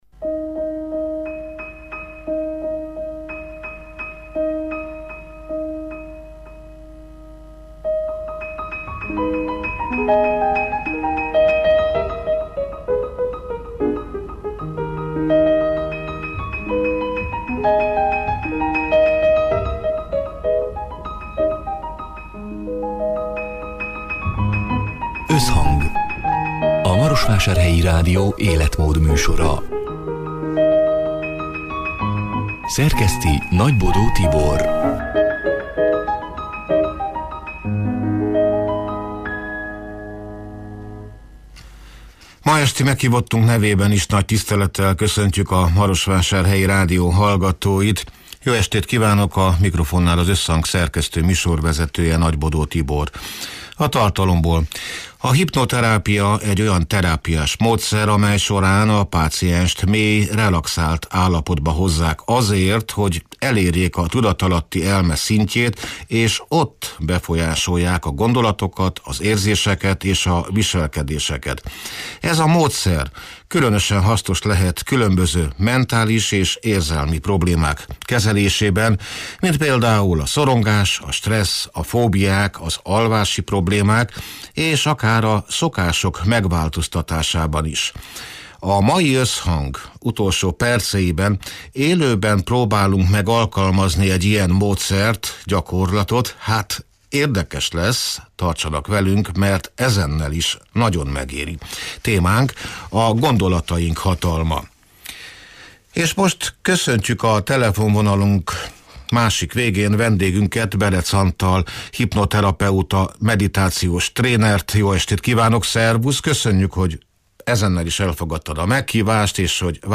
A soron következő Összhang utolsó perceiben élőben próbálunk meg alkalmazni egy ilyen módszert.